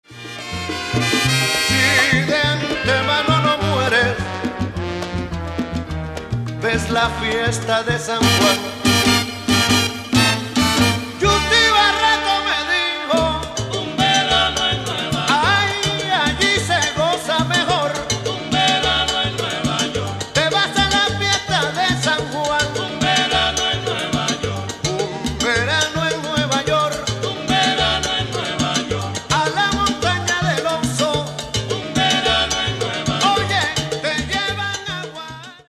Salsa de Porto-rico :